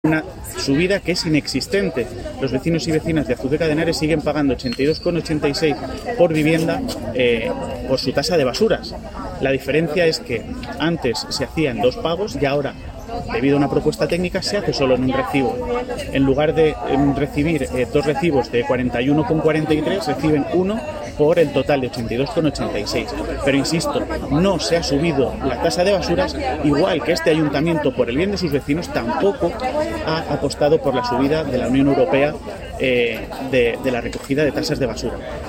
Declaraciones del alcalde sobre la tasa de basuras